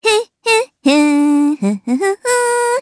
Cecilia-Vox_Hum_jp.wav